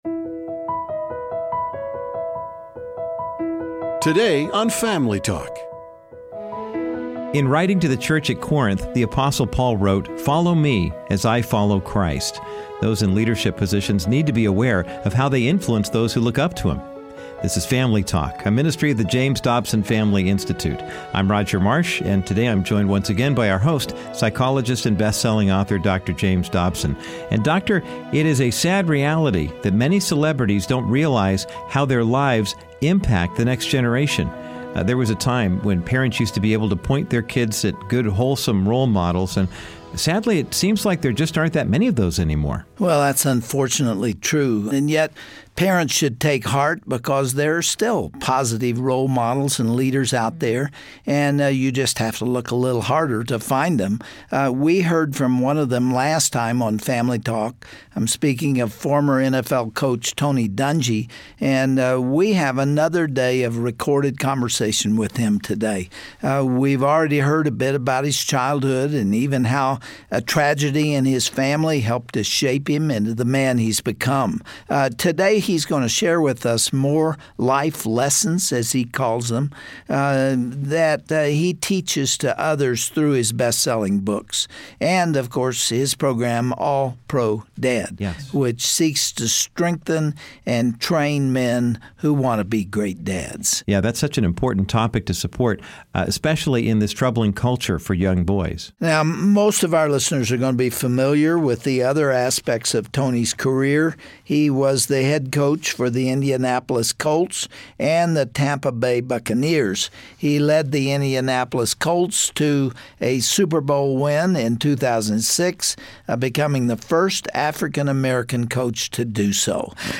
Dr. Dobson will continue his timeless interview with Super Bowl winning coach, Tony Dungy. Tony reflects on his playing and coaching careers, and shares that his greatest achievement is pointing people to Jesus.